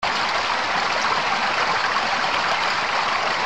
stream_large.mp3